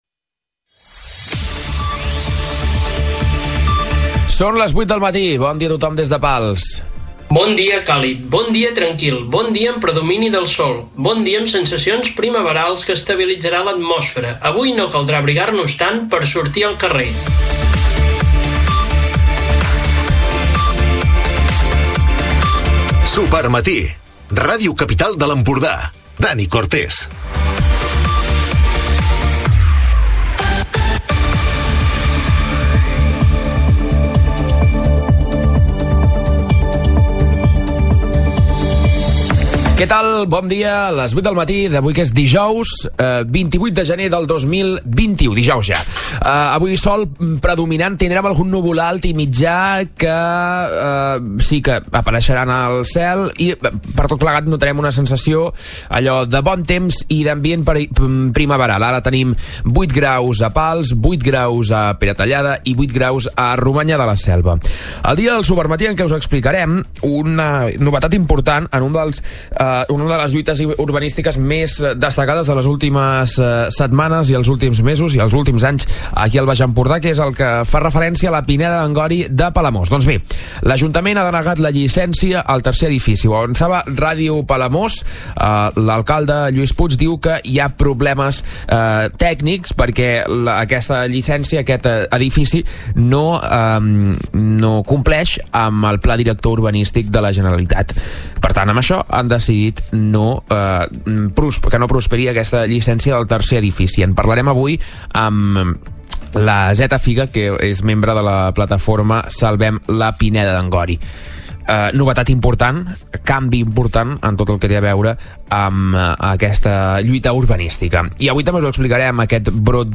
Recupera l'informatiu d'aquest dijous